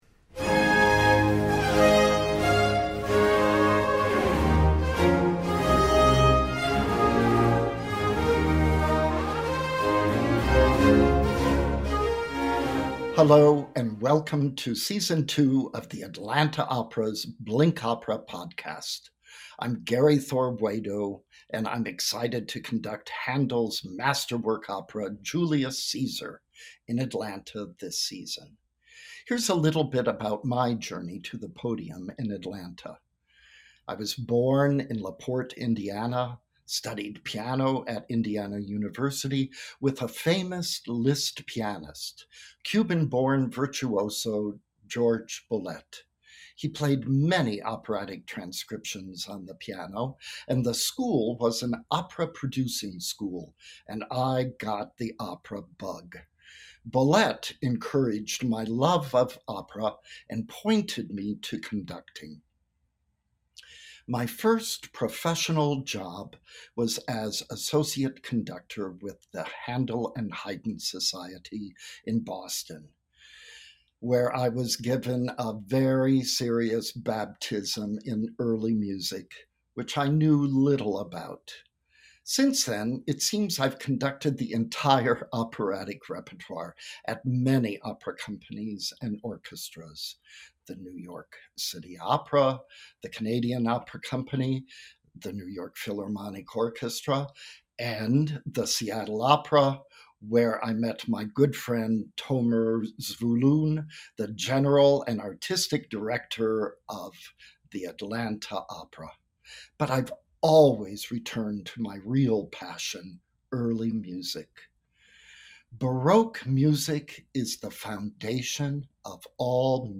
Blinks are built to give you an introduction to operas told from the artists who actually perform them and know them intimately. So, let’s listen to the highlights, unpack the plot, and hear about performance anecdotes in a time-efficient and fun way!